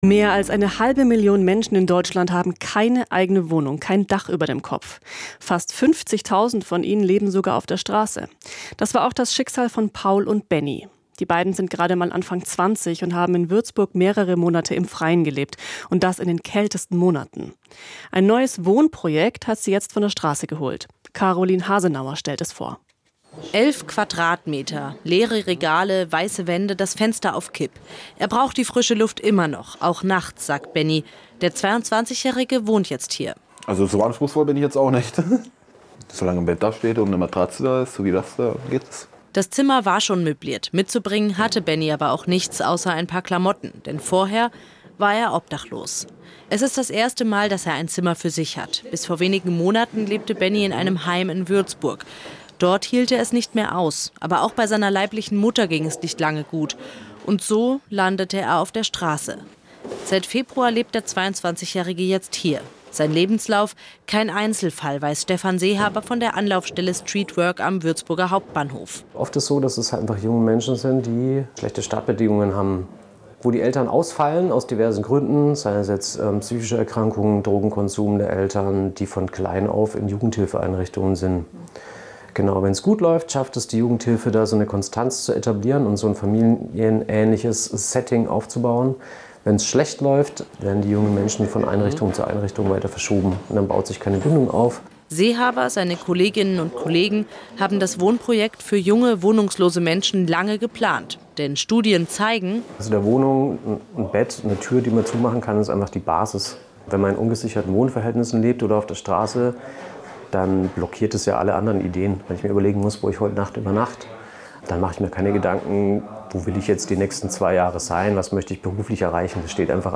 Dieser Radioeitrag zum Wohnprojekt lief am 3.3. um 12.39 Uhr auf Bayern 1-Mainfranken und am 4.3. um 12.47 Uhr auf BR24Radio